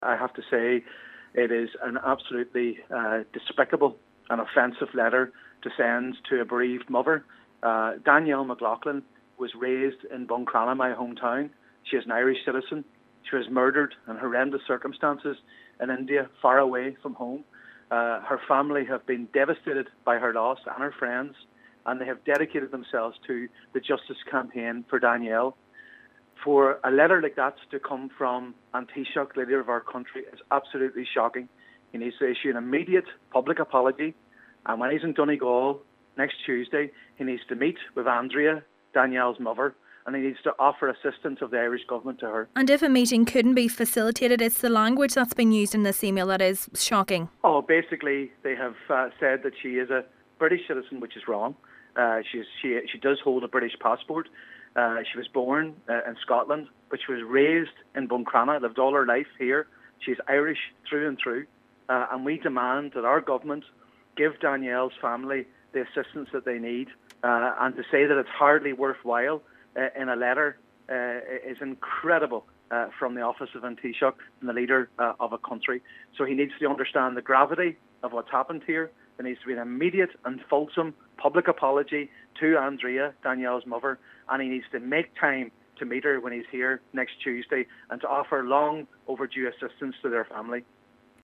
Donegal Senator Padraig MacLochlainn has called for an immediate apology to be issued: